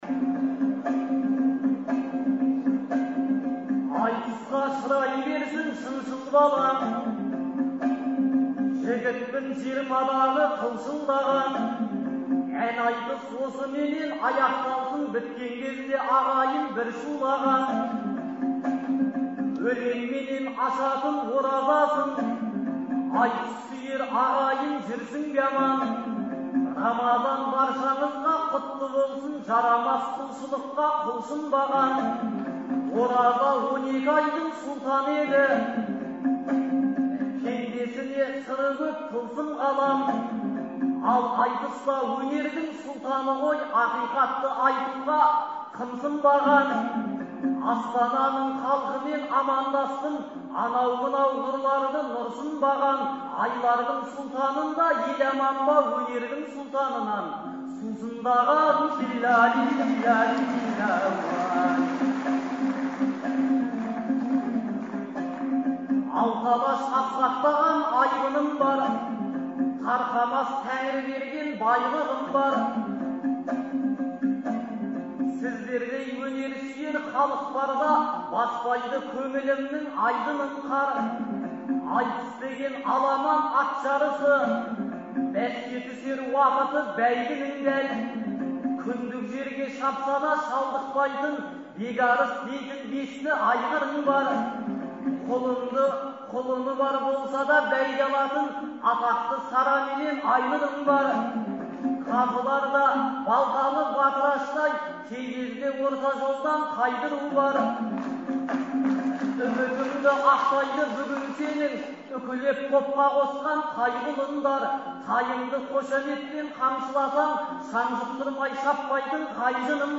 Шілденің 8-9-ы күндері Астанадағы «Қазақстан» орталық концерт залында «Ел, Елбасы, Астана» деген атпен ақындар айтысы өтті. Алғашқы күні айтысқан 20 ақынның арасынан іріктеліп шыққан он адам екінші күні бес жұп болды.